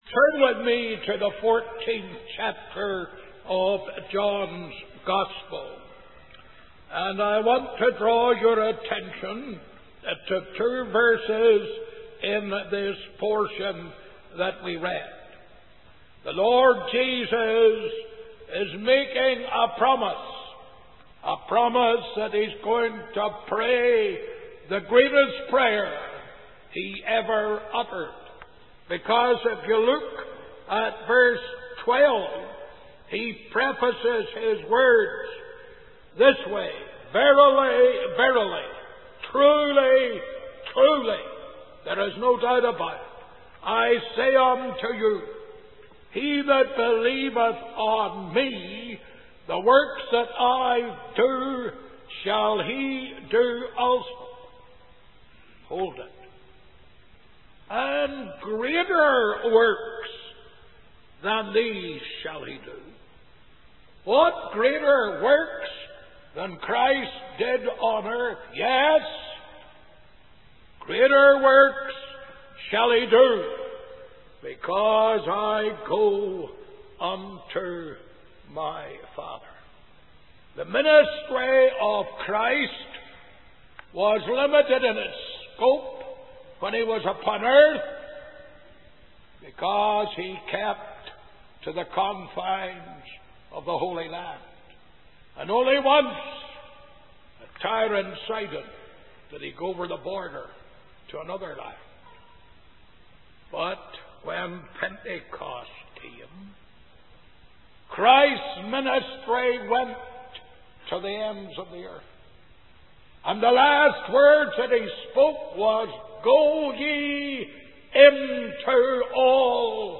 The Greatest Prayer Ever Offered by Ian Paisley | SermonIndex